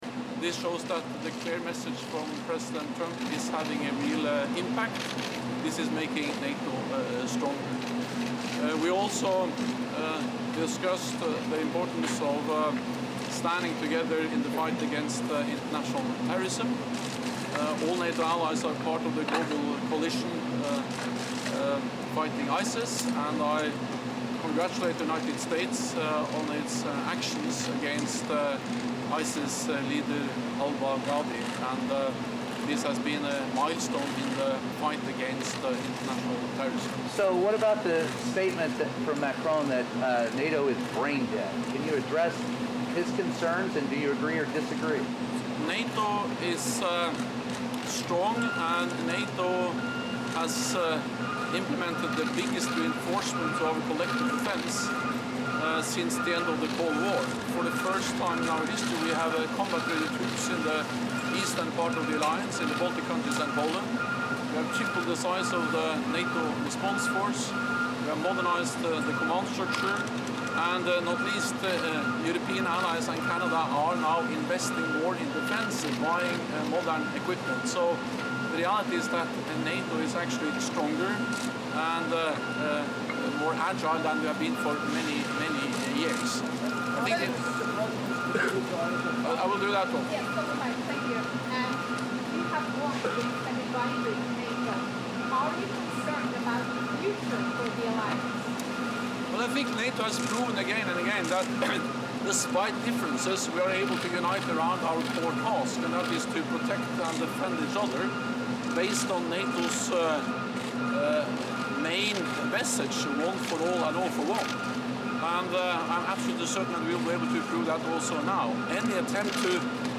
Statement to the press
by NATO Secretary General Jens Stoltenberg after meeting with US President Trump